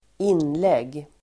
Uttal: [²'in:leg:]